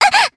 Aisha-Vox_Damage_jp_02.wav